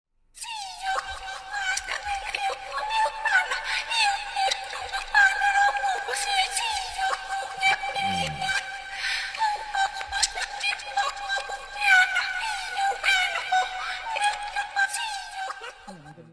เสียงเรียกเข้าติ๊กต๊อก